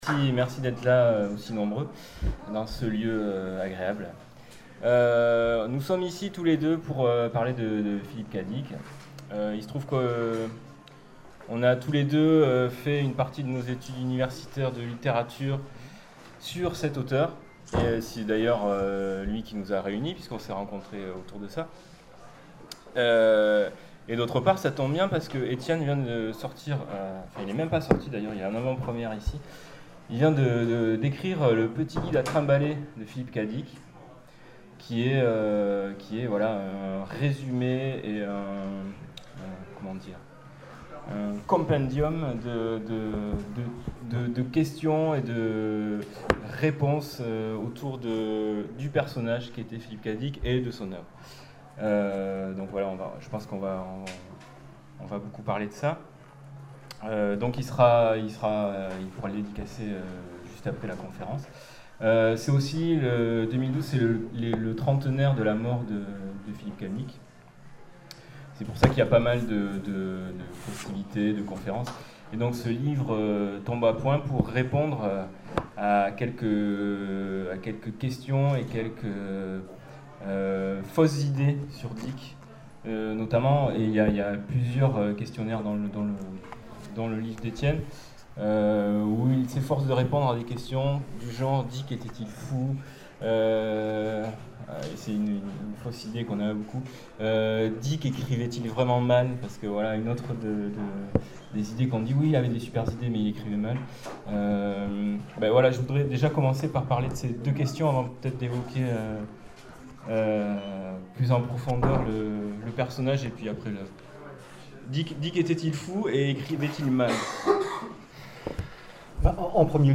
Festival de science fiction de Lyon : conférence Philip K. Dick et son univers